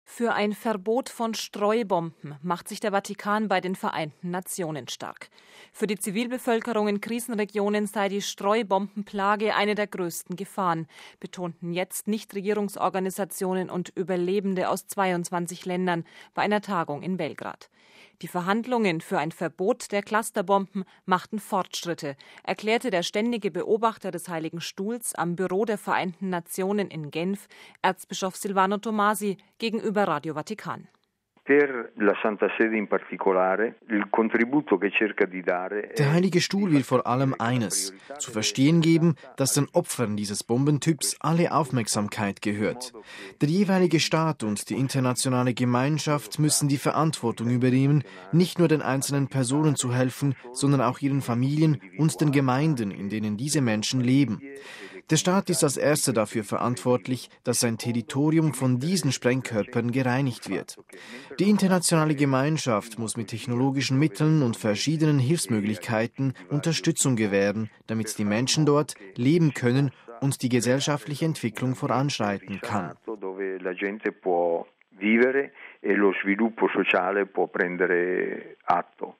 Die Verhandlungen für ein Verbot der "Clusterbomben" machten Fortschritte, erklärte der Ständige Beobachter des Heiligen Stuhls am Büro der Vereinten Nationen in Genf, Erzbischof Silvano Tomasi, gegenüber Radio Vatikan: